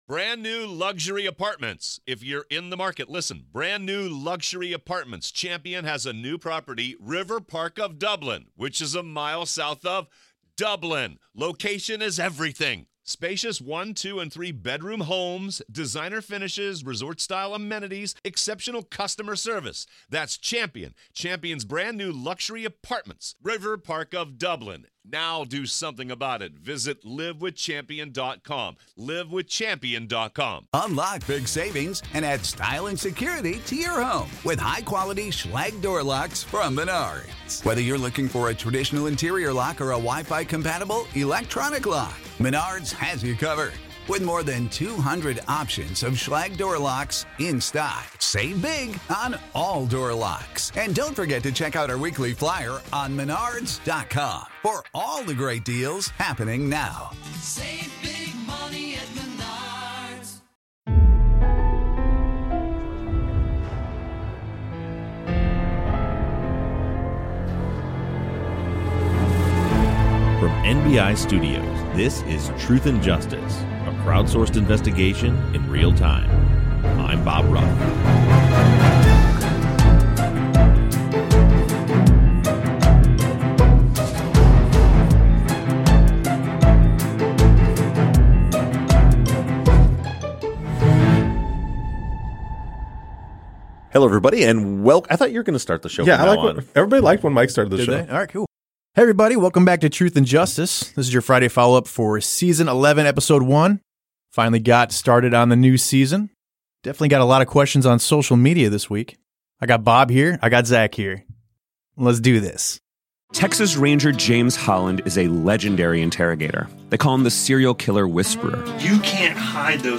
The guys discuss listener questions